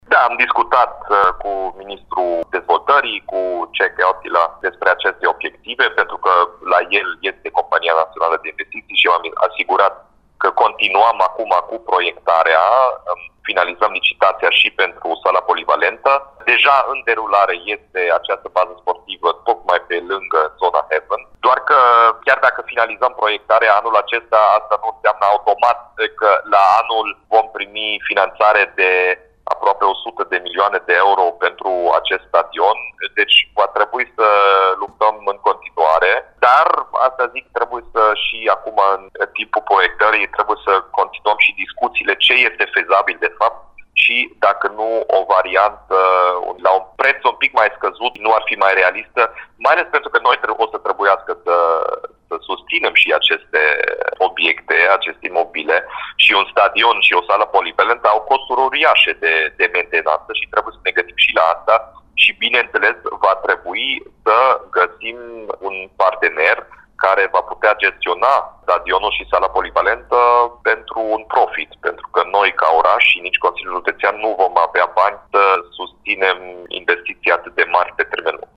Dominic Fritz – pentru declarația pe larg, acționați butonul „play” al fișierului AUDIO de mai jos
„Nu cred că este fezabil ca politicul să facă parte din conducerea acestor cluburi, fie direct sau indirect, pentru că politica nu are ce căuta în sport”, a concluzionat Dominic Fritz, într-un interviu pentru Radio Timișoara.